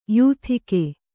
♪ yūthike